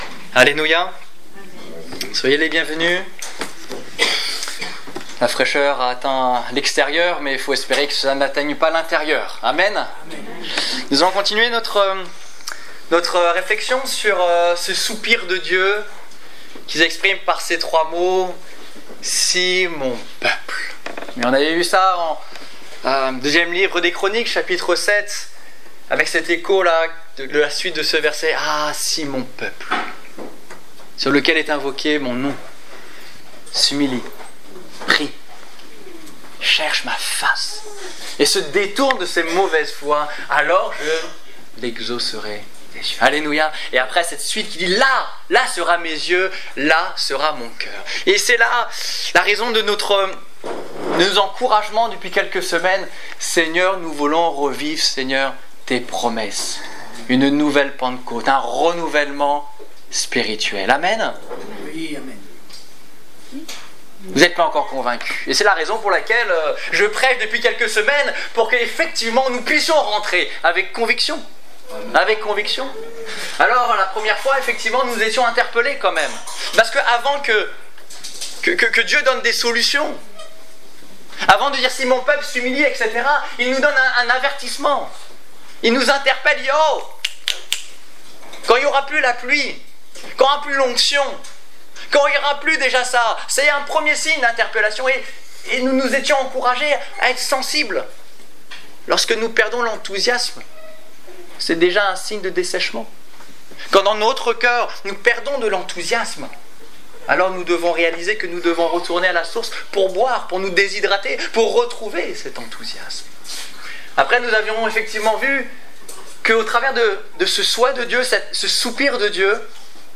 Culte du 16 novembre 2014 Ecoutez l'enregistrement de ce message à l'aide du lecteur